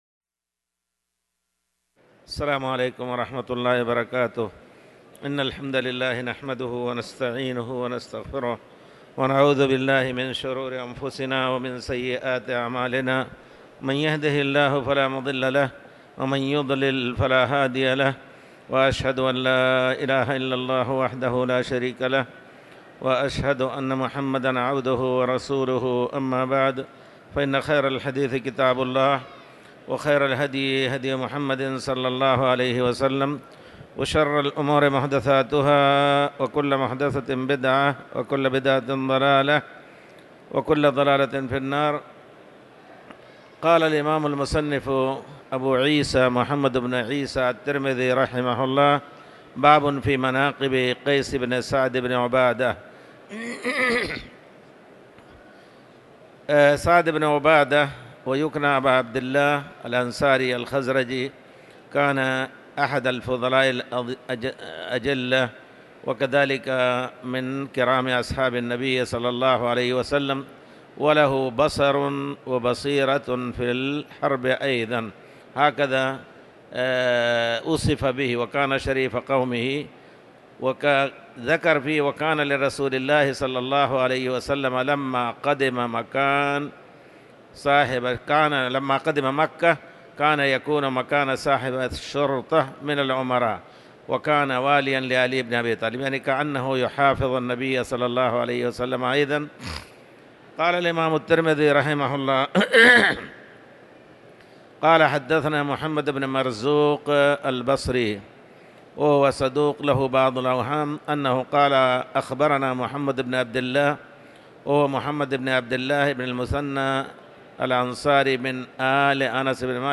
تاريخ النشر ٢٨ رجب ١٤٤٠ هـ المكان: المسجد الحرام الشيخ